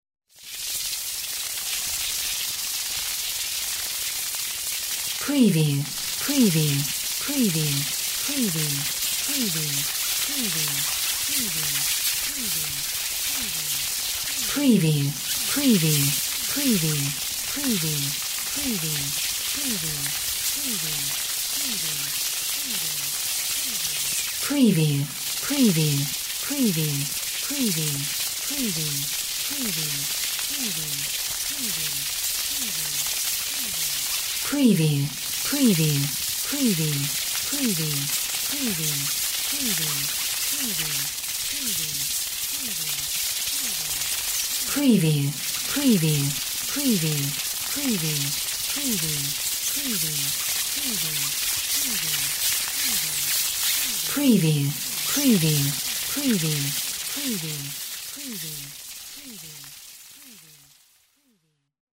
Bats swarming 1B
Stereo sound effect - Wav.16 bit/44.1 KHz and Mp3 128 Kbps
PREVIEWANM_BATS_SWARM_WBHD01B.mp3